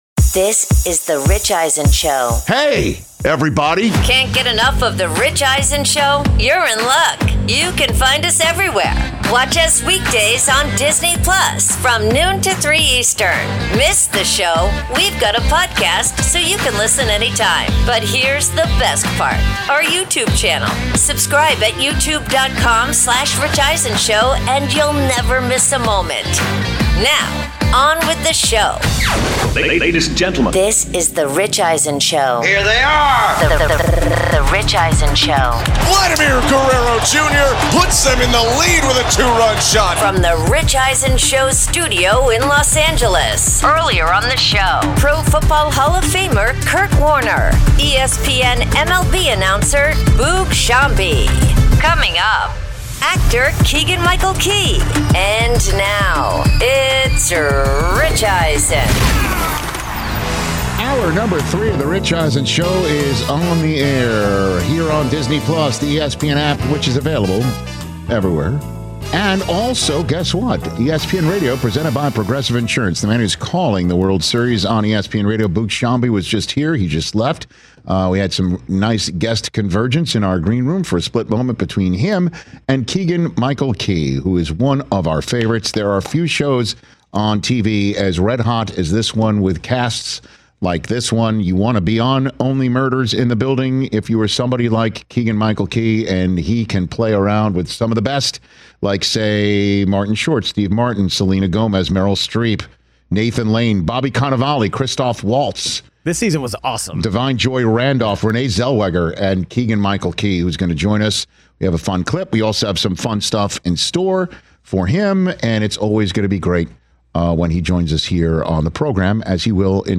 Hour 3: Keegan-Michael Key In-Studio, plus the NBA’s Big Travelling Problem Podcast with Rich Eisen